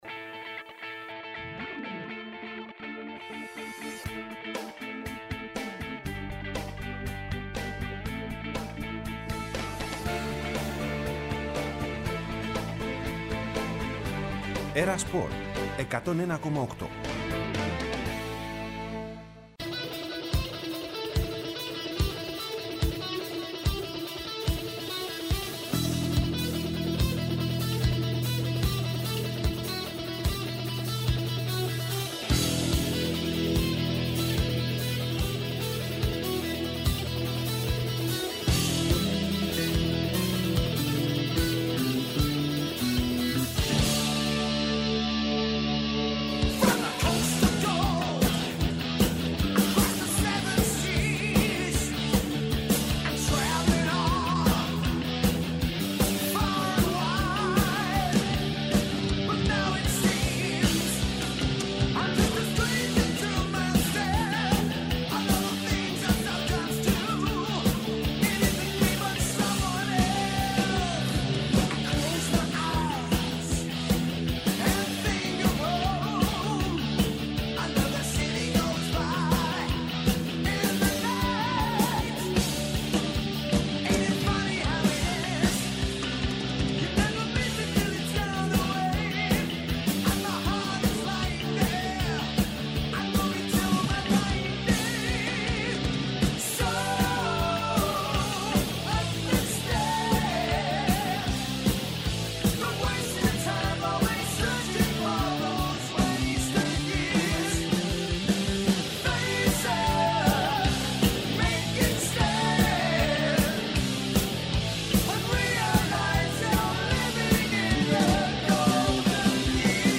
Συνεντεύξεις και ρεπορτάζ για όσα συμβαίνουν εντός και εκτός γηπέδων.